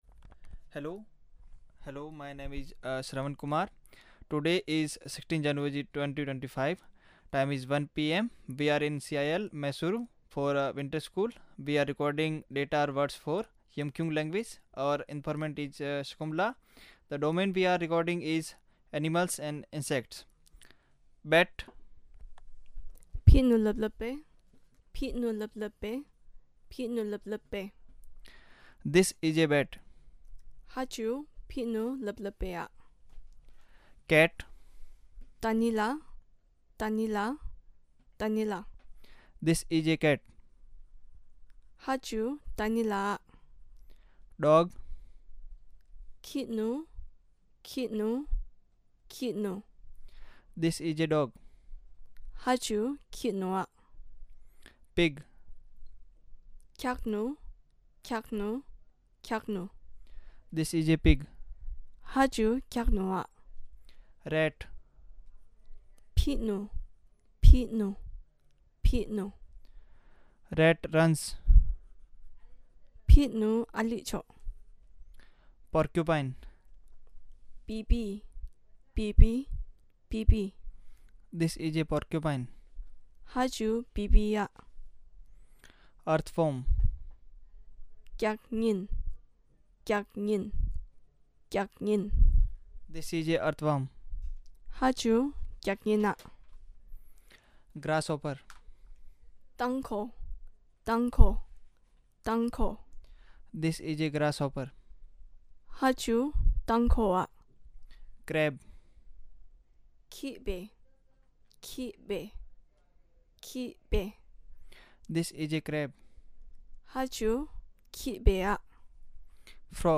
Elicitation of words related to animals